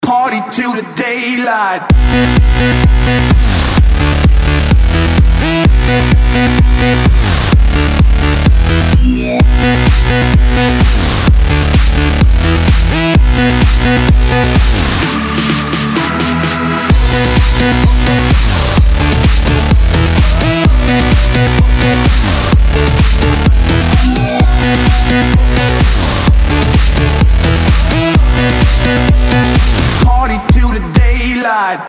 • Скачать рингтон из: Клубные